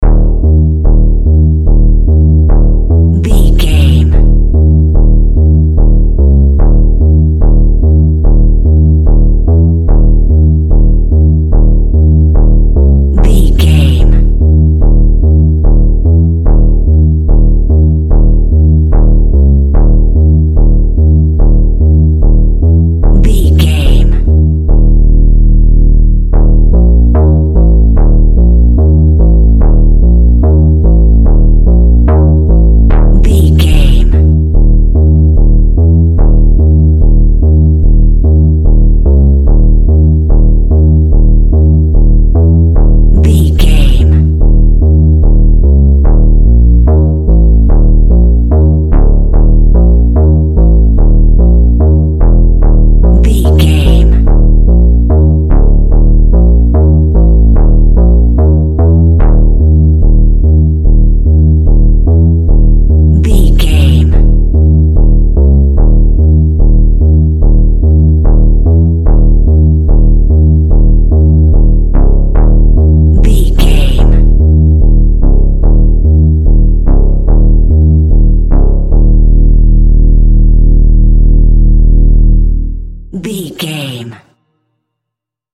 In-crescendo
Thriller
Aeolian/Minor
Slow
ominous
dark
suspense
haunting
eerie
Horror synth
Horror Ambience
electronics
synthesizer